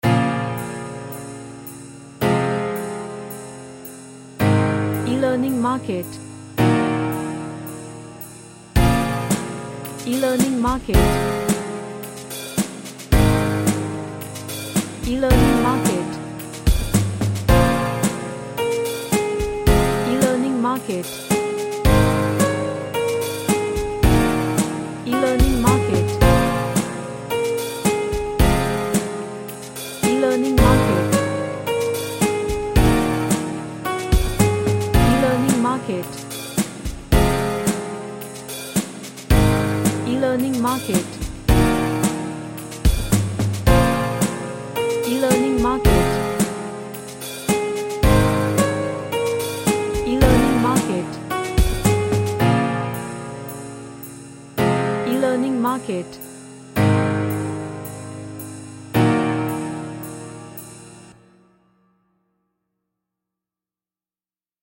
A nice piano instrumental.
Happy